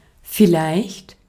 Ääntäminen
IPA: [fi.ˈlaɪçt]